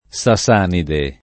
Sasanide [ S a S# nide ] → Sassanide